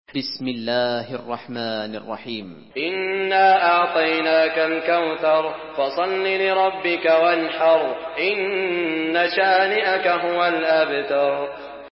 سورة الكوثر MP3 بصوت سعود الشريم برواية حفص
مرتل